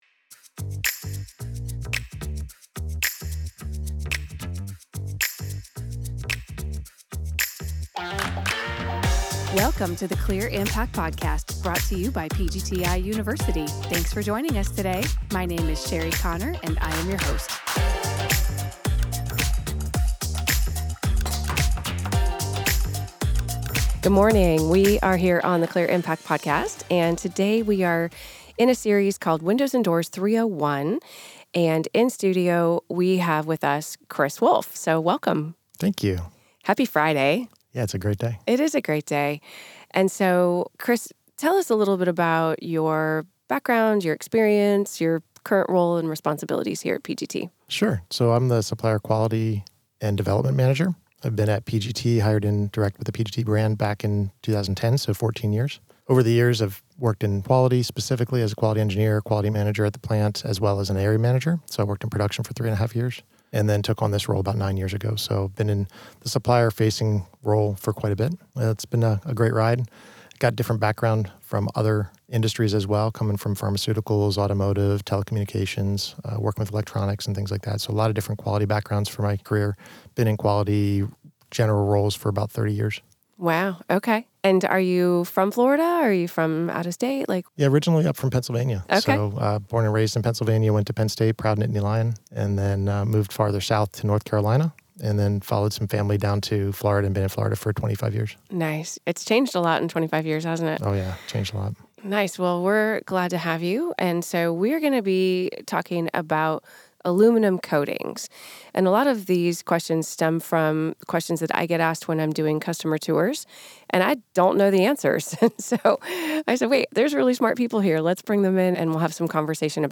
This is a great conversation, and you’ll learn a lot!